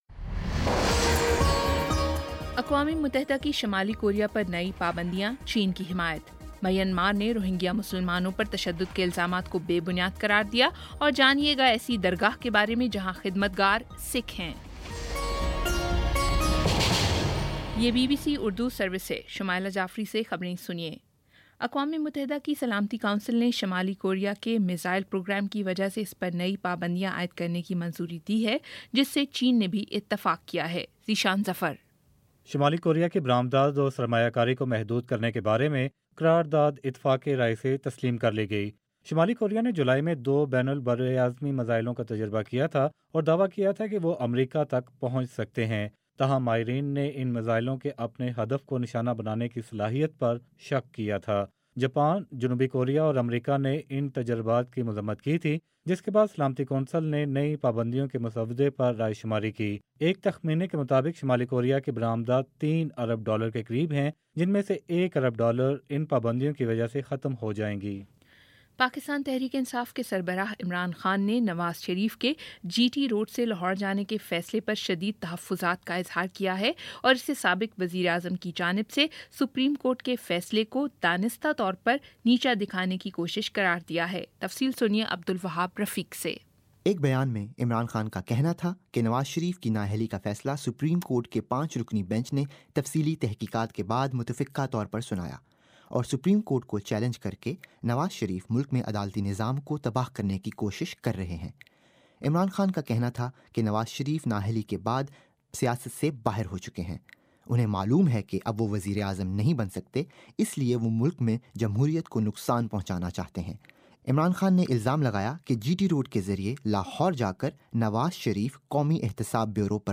اگست 06 : شام سات بجے کا نیوز بُلیٹن